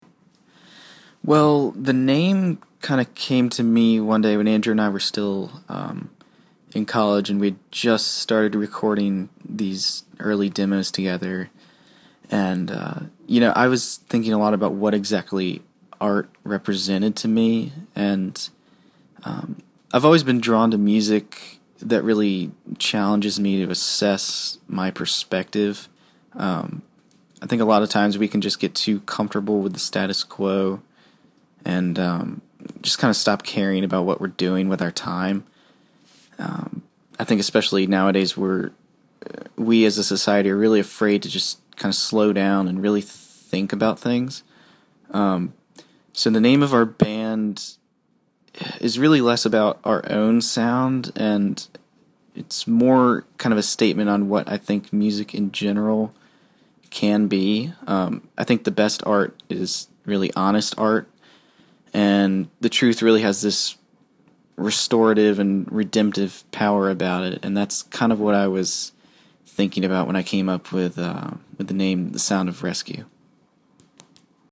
THE SOUND OF RESCUE INTERVIEW – August 2012